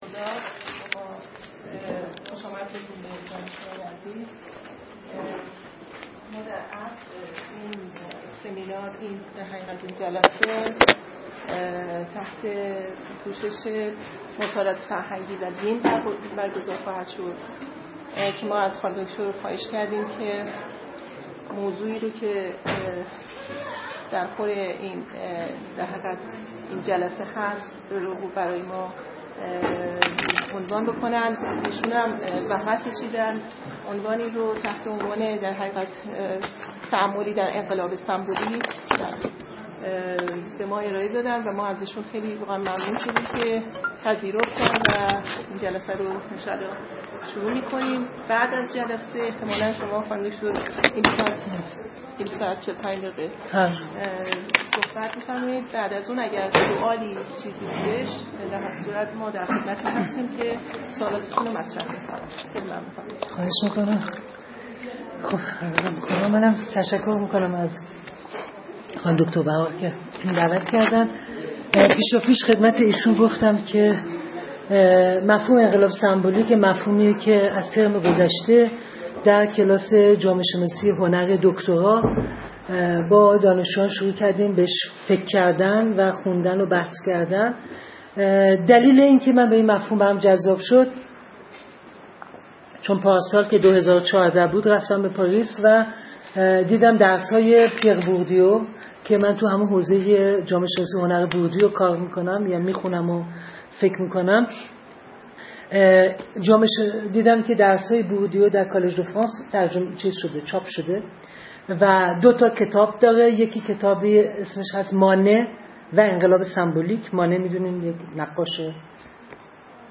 سخنرانی
در انجمن مطالعات فرهنگی و ارتباطات دانشکده علوم اجتماعی برگزار شد.